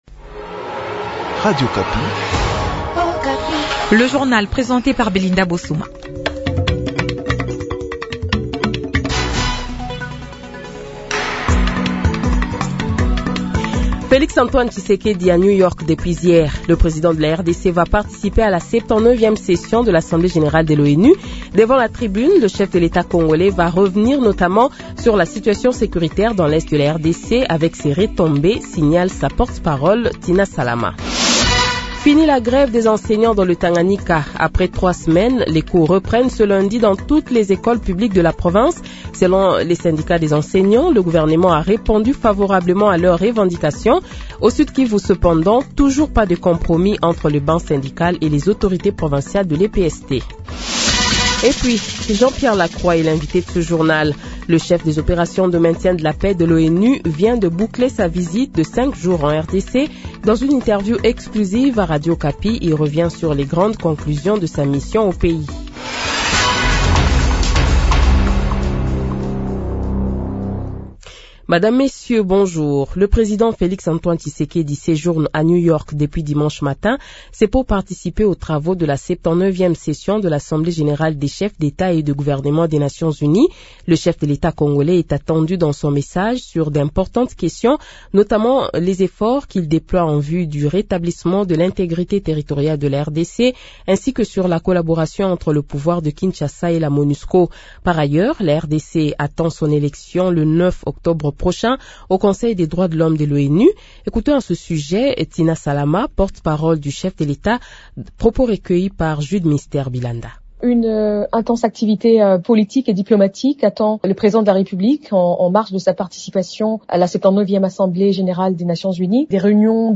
Journal Matin 6 heures et 7 heures